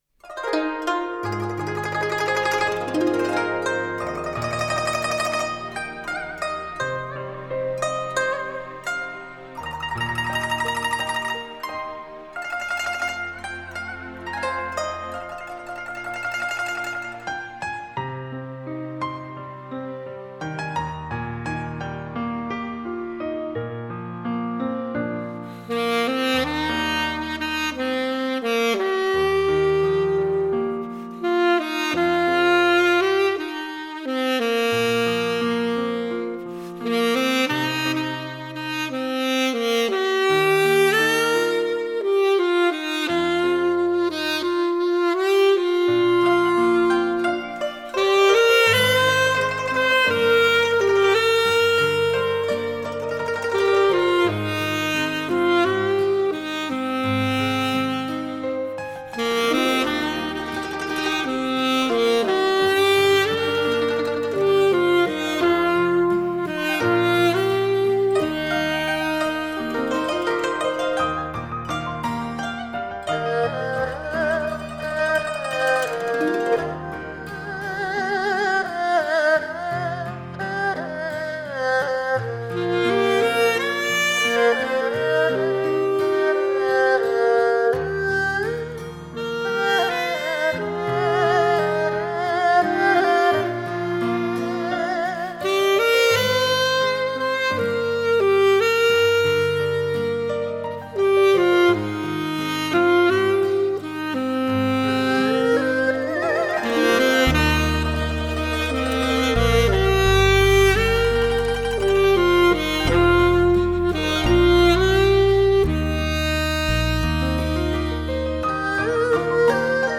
三百平米SSL录音棚完美收录
永恒经典的华语金曲 浪漫温馨的完美演绎
古筝 二胡 最具中国风的萨克斯专辑
铜味十足的优雅旋律 传真度极高的完美录音